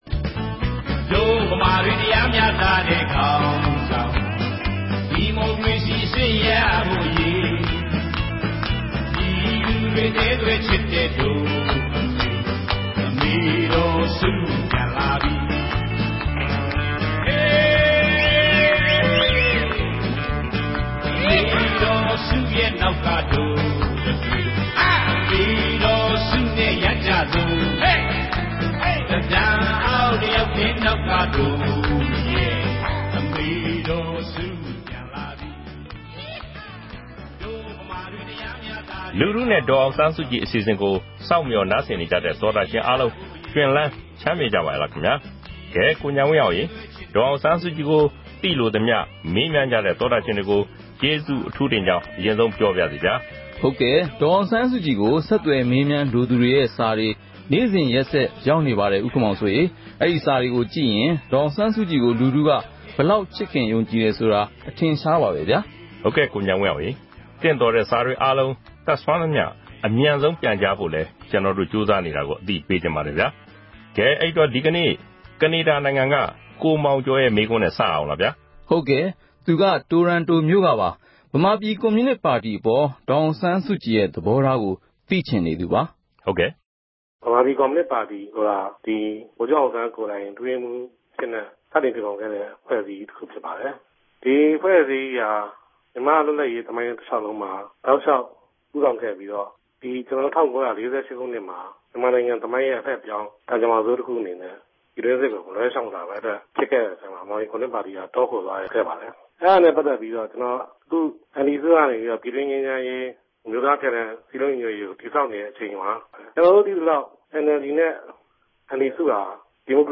ဒီ အစီအစဉ်ကနေ ပြည်သူတွေ သိချင်တဲ့ မေးခွန်းတွေကို ဒေါ်အောင်ဆန်းစုကြည် ကိုယ်တိုင် ဖြေကြားပေးမှာ ဖြစ်ပါတယ်။
အဲဒီ တယ်လီဖုန်း နံပါတ်ကို RFA က ဆက်သွယ်ပြီး ကာယကံရှင်ရဲ့ မေးမြန်းစကားတွေကို အသံဖမ်းယူကာ ဒေါ်အောင်ဆန်းစုကြည်ရဲ့ ဖြေကြားချက်နဲ့အတူ ထုတ်လွှင့်ပေးမှာ ဖြစ်ပါတယ်။